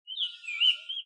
描述：一个空的火车站的领域记录在布宜诺斯艾利斯，阿根廷。
Tag: 现场录音 街道 火车站 城市